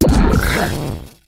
bellibolt_ambient.ogg